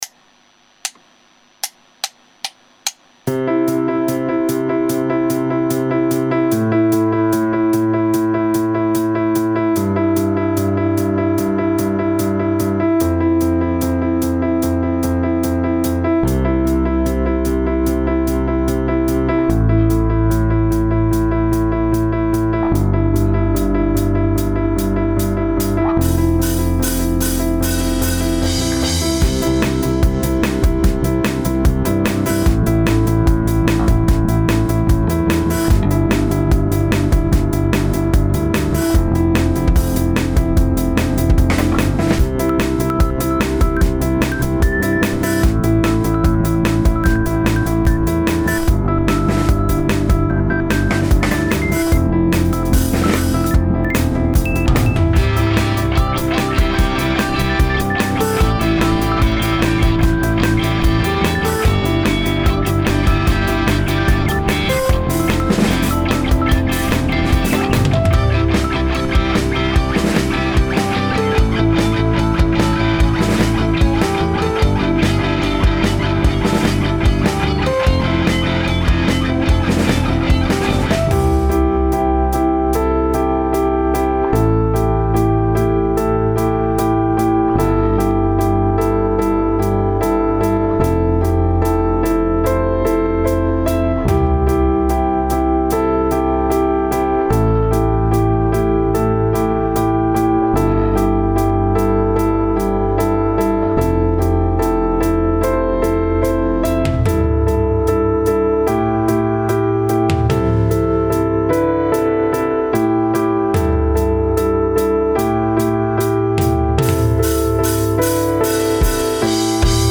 Piano_Work_02.mp3